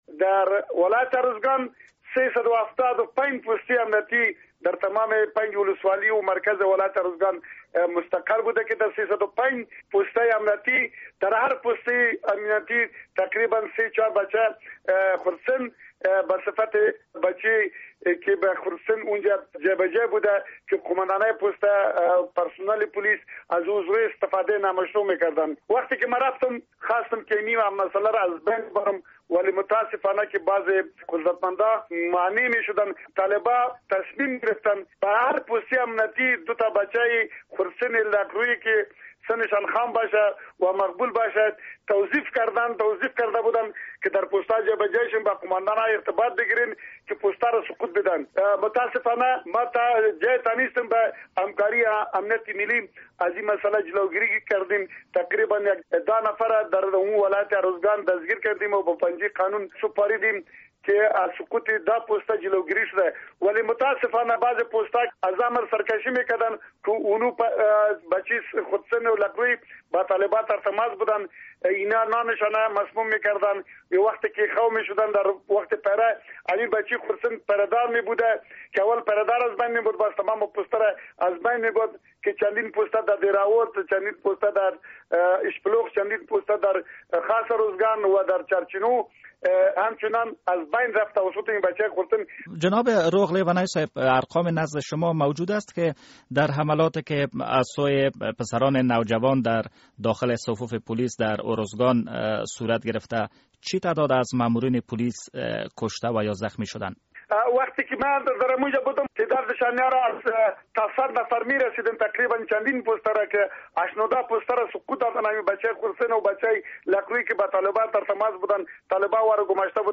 مصاحبه با غلام سخی روغ لیونی: